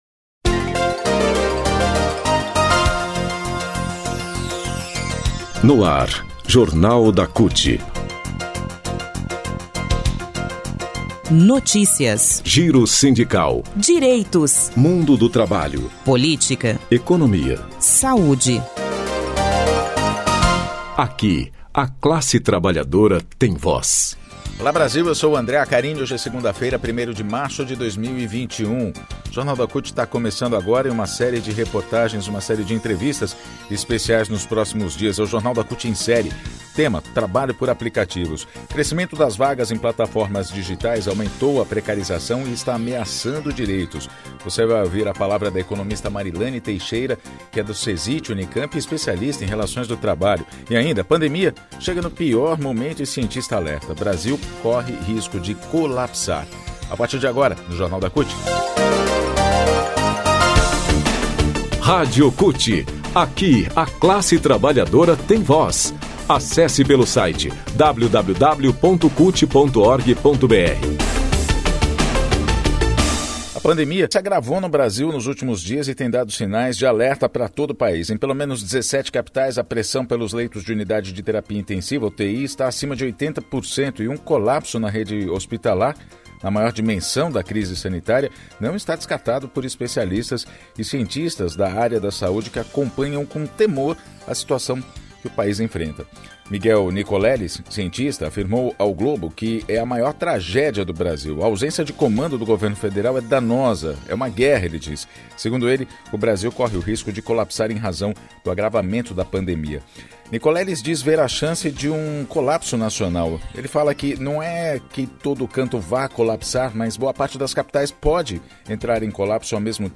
Jorna de rádio da CUT -